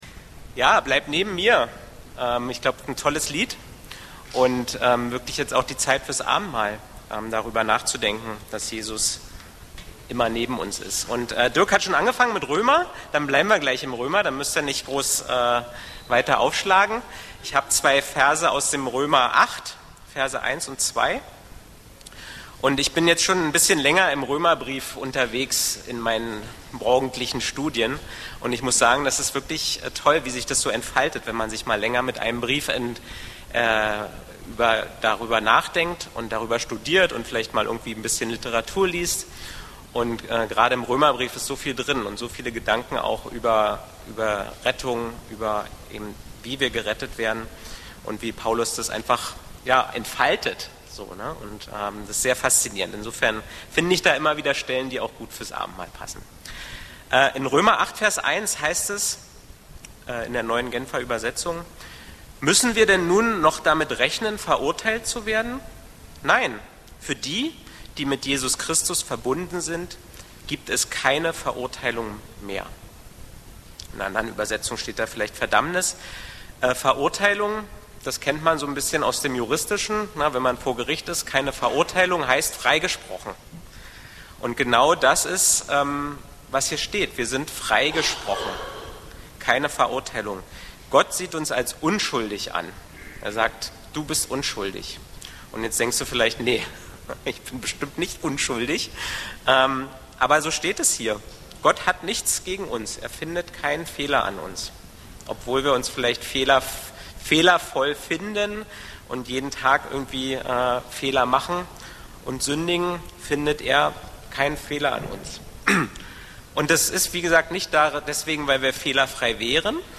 E-Mail Details Predigtserie